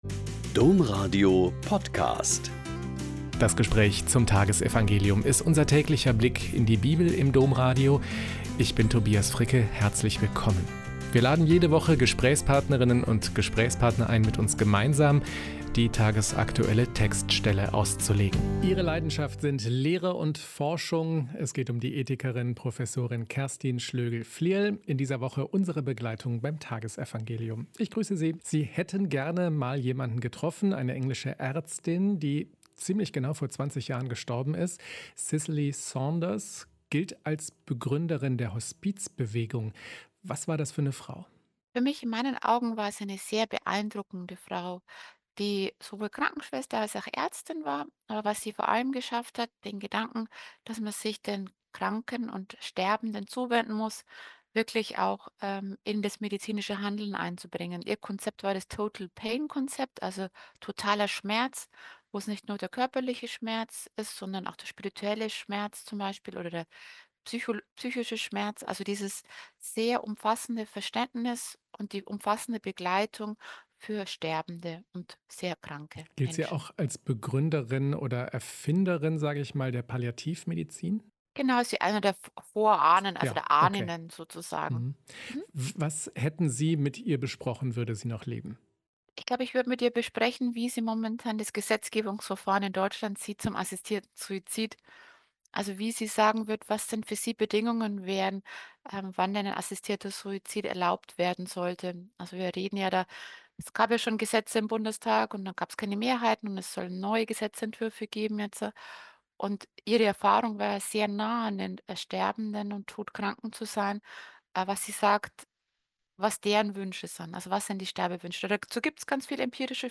Mt 10,7-15 - Gespräch